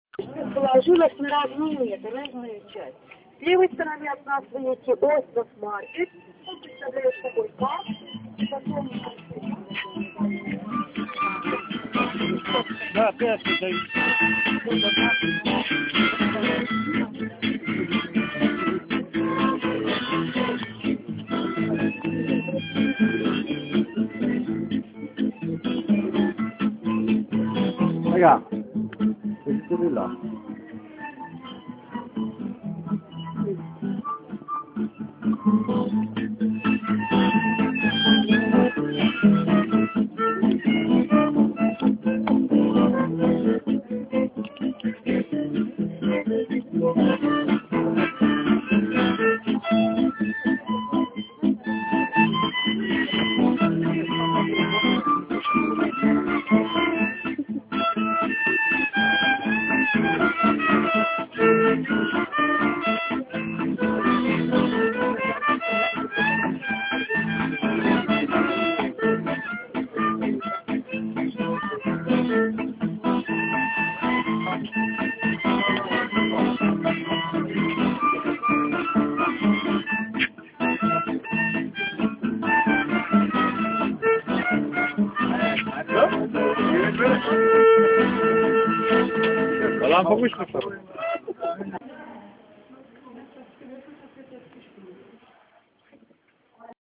E basta un cellulare, se non si ha un registratore professionale.
Budapest. Bastione dei Pescatori a gennaio.
Il posto ideale per girare un cappello a terra e raccattare qualche moneta. Il leggero ritardo nell'esposizione del tema da parte del violinista è la prova provata del sangue magiaro.